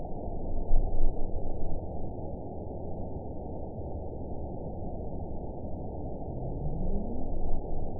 event 910687 date 01/29/22 time 01:57:46 GMT (3 years, 10 months ago) score 8.88 location TSS-AB07 detected by nrw target species NRW annotations +NRW Spectrogram: Frequency (kHz) vs. Time (s) audio not available .wav